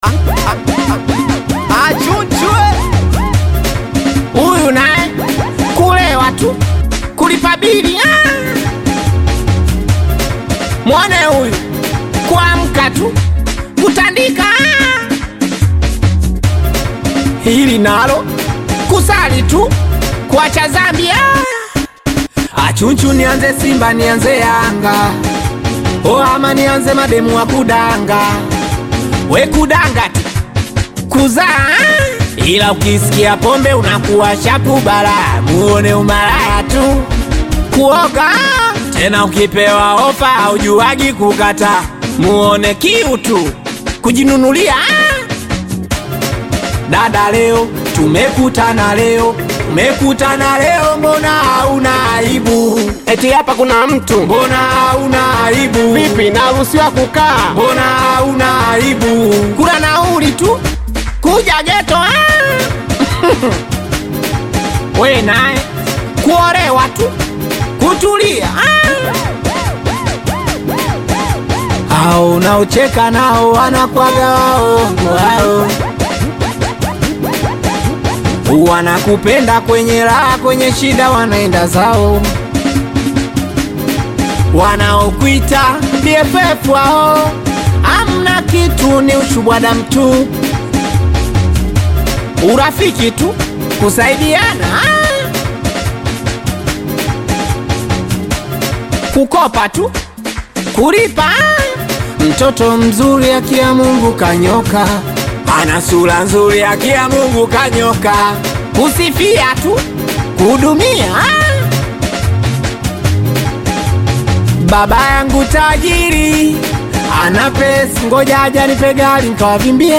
Tanzanian Bongo Flava Singeli